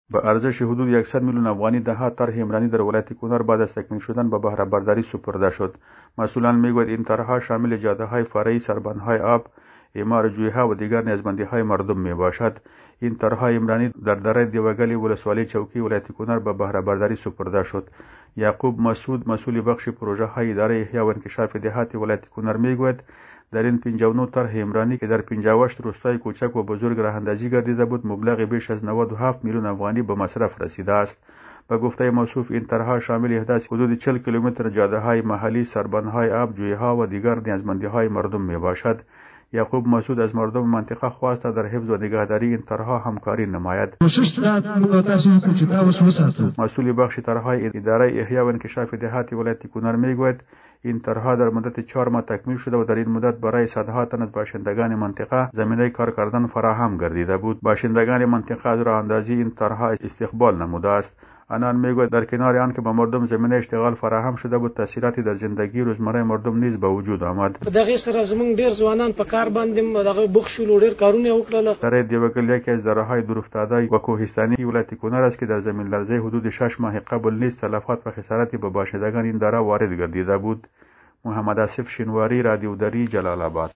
خبر / افغانستان